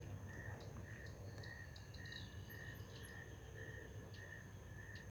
Fork-tailed Flycatcher (Tyrannus savana)
Condition: Wild
Certainty: Observed, Recorded vocal